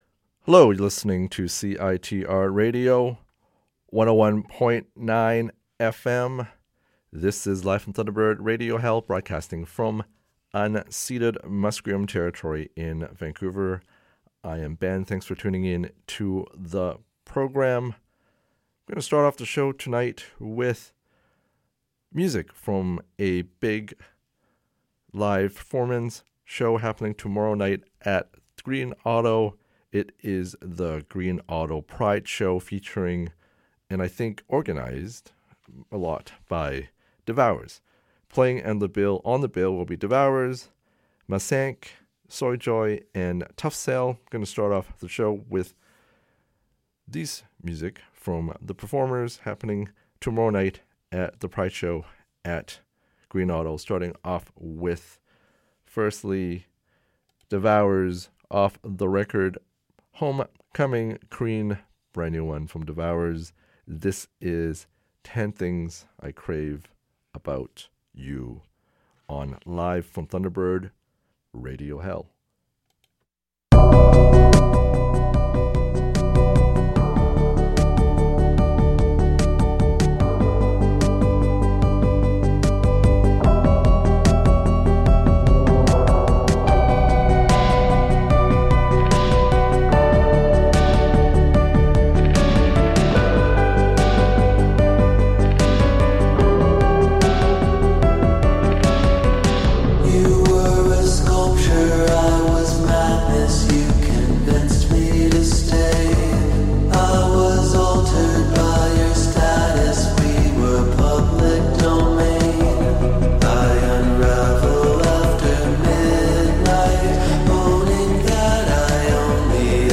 Live From Thunderbird Radio Hell Episode August 3, 2023 August 4 2023 Episode 8:59pm - 10:26pm A lot of music.